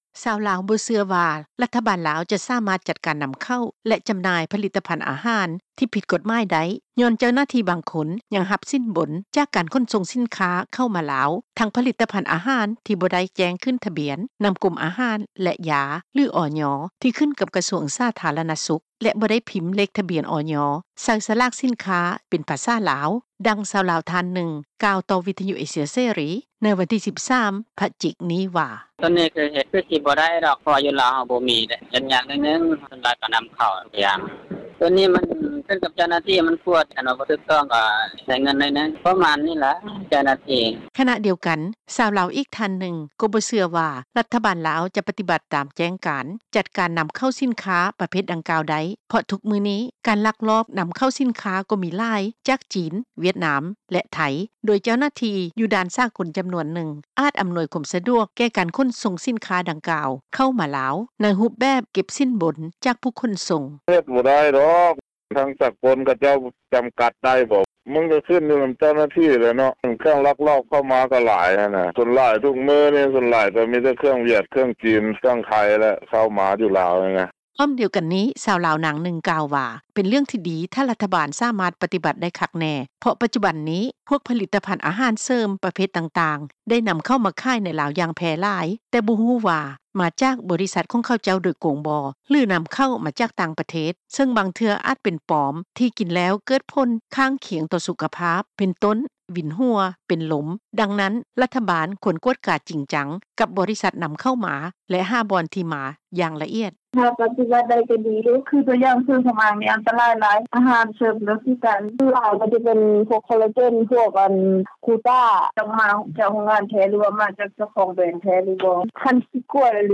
ຊາວລາວ ບໍ່ເຊື່ອວ່າ ລັດຖະບານລາວ ຈະສາມາດຈັດ ການນຳເຂົ້າ ແລະຈຳໜ່າຍ ຜະລິດຕະພັນອາຫານ ທີ່ຜິດກົດໝາຍໄດ້ ຍ້ອນ ເຈົ້າໜ້າທີ່ບາງຄົນຍັງຮັບສິນບົນ ຈາກການຂົນສົ່ງສິນຄ້າເຂົ້າມາລາວ ທັງຜະລິດຕະພັນອາຫານ ທີ່ບໍ່ໄດ້ແຈ້ງຂຶ້ນທະບຽນ ນຳກົມອາຫານ ແລະ ຢາ ຫຼື ອຢ ທີ່ຂຶ້ນກັບກະຊວງສາທາລະນະສຸກ, ແລະບໍ່ໄດ້ພິມເລກທະບຽນ ອຢ. ໃສ່ສະຫຼາກສິນຄ້າ ເປັນພາສາລາວ, ດັ່ງ ຊາວລາວ ທ່ານໜຶ່ງ ກ່າວຕໍ່ວິທຍຸເອເຊັຽເສຣີ ໃນວັນທີ 13 ພະຈິກ ນີ້ວ່າ:
ພ້ອມດຽວກັນນີ້ ຊາວລາວ ນາງໜຶ່ງ ກ່າວວ່າ ເປັນເລື່ອງທີ່ດີຖ້າລັດຖະບານ ສາມາດປະຕິບັດໄດ້ຄັກແນ່ ເພາະປັດຈຸບັນນີ້ ພວກຜະລິດຕະພັນອາຫານເສີມປະເພດຕ່າງໆ ໄດ້ນຳມາຂາຍໃນລາວຢ່າງແຜ່ຫຼາຍ ແຕ່ບໍ່ຮູ້ວ່າ  ມາຈາກບໍລິສັດຂອງເຂົາເຈົ້າໂດຍກົງບໍ່ ຫຼືນຳເຂົ້າມາຈາກຕ່າງປະເທດ ເຊິ່ງບາງເທື່ອອາດເປັນປອມ ທີ່ກິນແລ້ວເກີດຜົນຂ້າງຄຽງຕໍ່ສຸຂະພາບ ເປັນຕົ້ນ ວິນຫົວ ເປັນລົມ ດັ່ງນັ້ນ ລັດຖະບານຄວນກວດກາຈິງຈັງ ກັບບໍລິສັດນຳເຂົ້າມາ ແລະຫາບ່ອນທີ່ມາ ຢ່າງລະອຽດ: